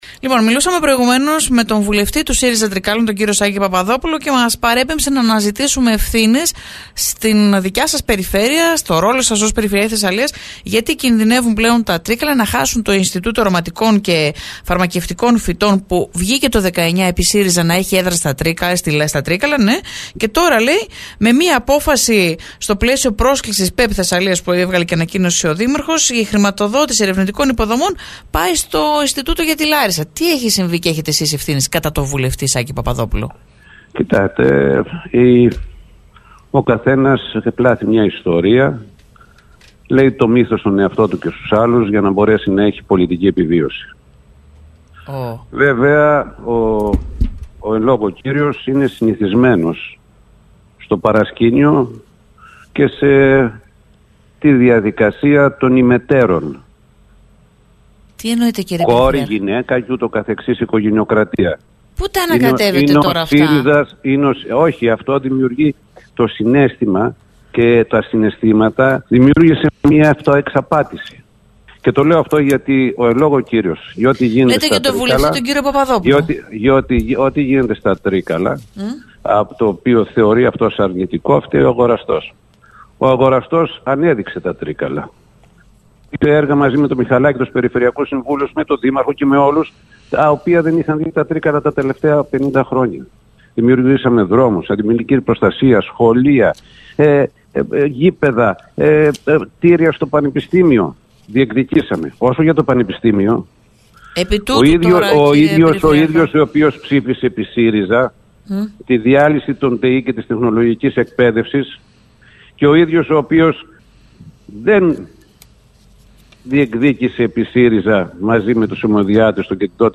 Αιχμές στον Περιφερειάρχη Θεσσαλίας Κώστα Αγοραστό για το θέμα του Ινστιτούτου Φαρμακευτικών και Αρωματικών Φυτών άφησε μιλώντας σήμερα στη Ραδιοφωνική Λέσχη 97,6 ο βουλευτής ΣΥΡΙΖΑ Σάκης Παπαδόπουλος: